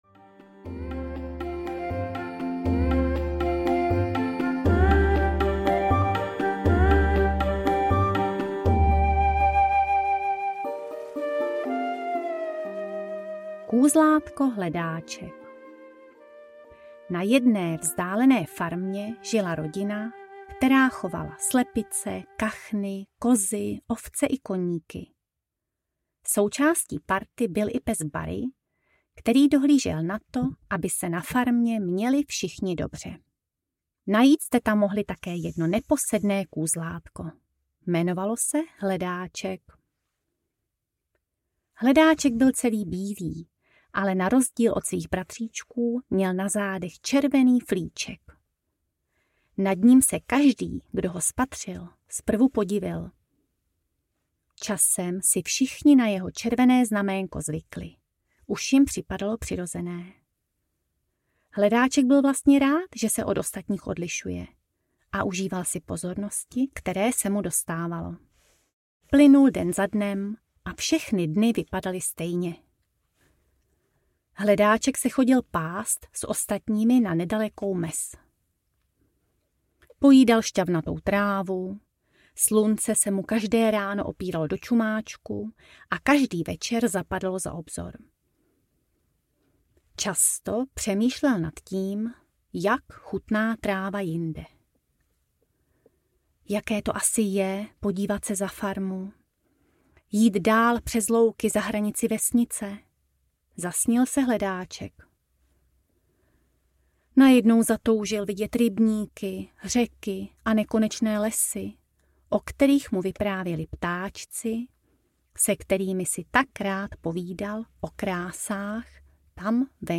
Kategorie: Dětské